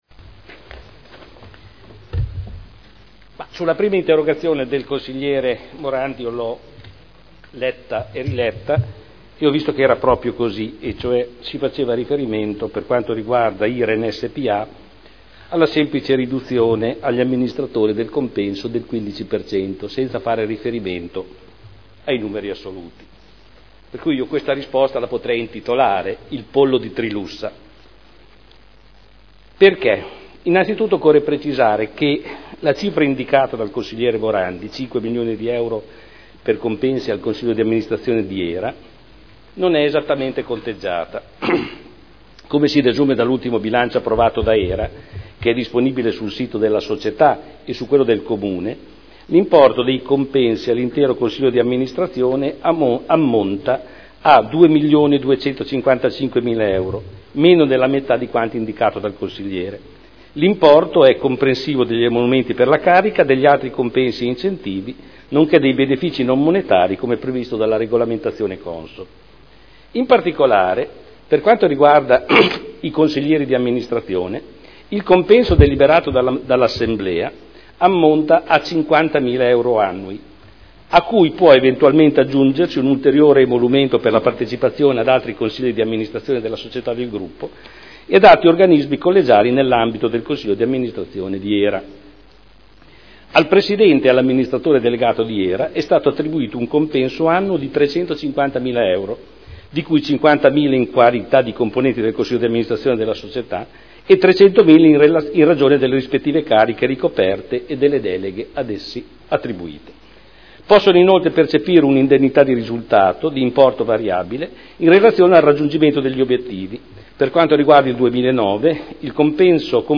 Giorgio Pighi — Sito Audio Consiglio Comunale
Seduta del 28/02/2011.